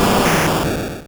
Cri d'Arbok dans Pokémon Rouge et Bleu.